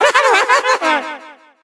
evil_gene_vo_08.ogg